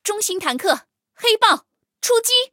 黑豹出击语音.OGG